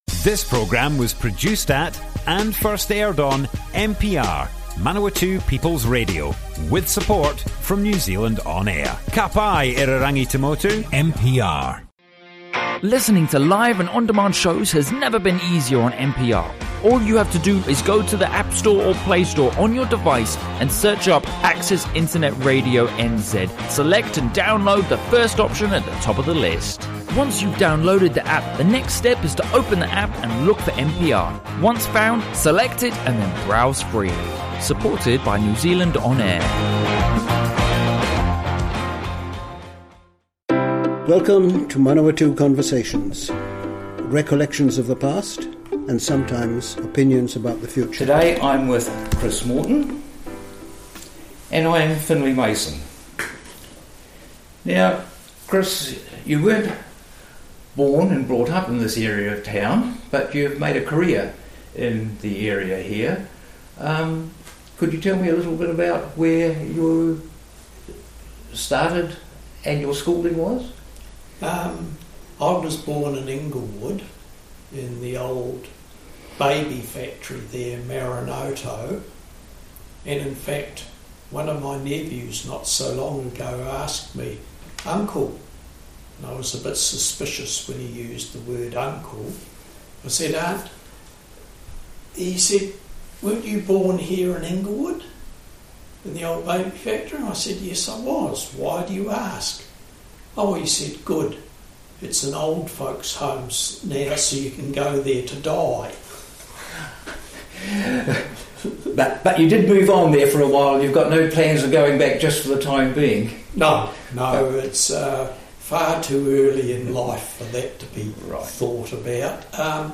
Manawatū Conversations More Info → Description Broadcast on Manawatū People's Radio, 10th September 2019.
oral histopry